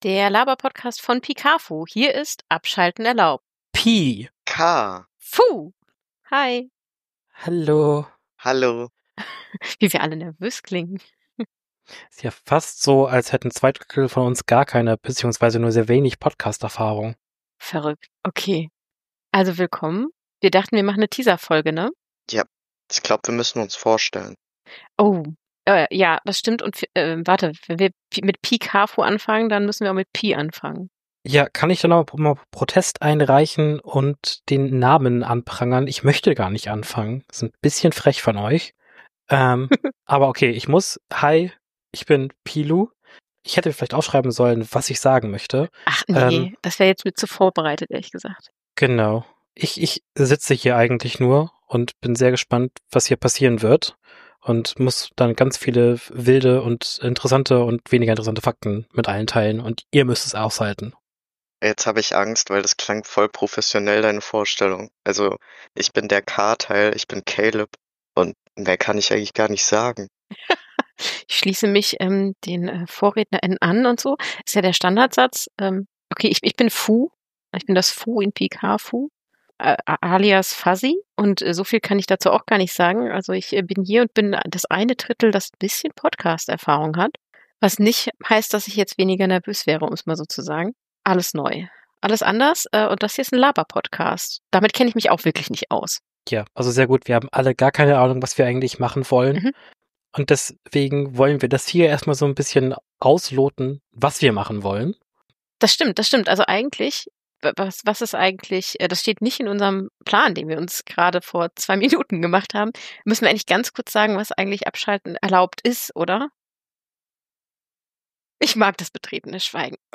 Wir stellen uns hier kurz vor und geben einen Ausblick auf das, was euch bei uns erwartet. Außerdem nennen wir unsere Grundsätze, erläutern worum es im Podcast geht und warum wir ihn machen. "Abschalten erlaubt" bietet einen Raum, um ein bisschen abzuschalten und aktiv positive Gespräche zu führen oder ihnen zuzuhören.